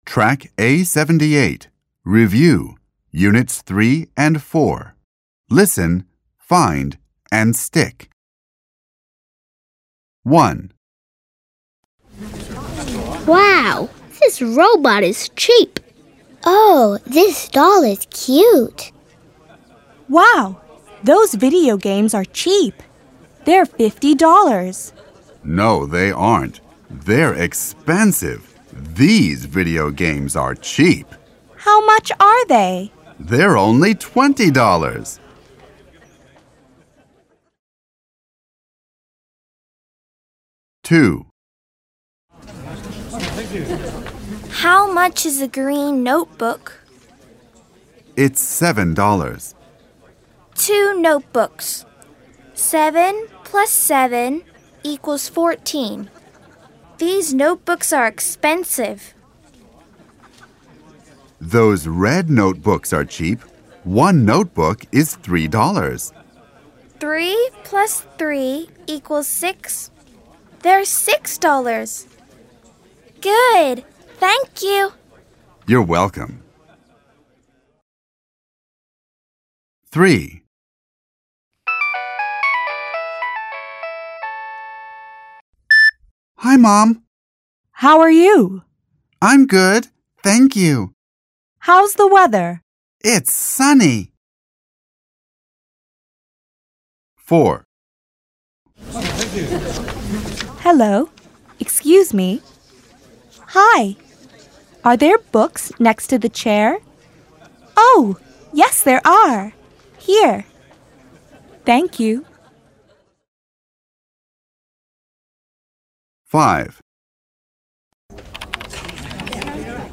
این مجموعه با لهجه ی  امریکن و توسط نویسندگانی چون Beat Eisele, Catherine Yang Eisele, Stephen M. Hanlon, Rebecca York Hanlon به رشته تحریر درآمده است و همچنین دارای سطح بندی از مبتدی تا پیشرفته می باشد و سراسر مملو از داستان های جذاب با کاراکتر های دوست داشتنی می باشد که یادگیری و آموزش زبان انگلیسی را برای کودکان ساده و لذت بخش تر خواهد کرد.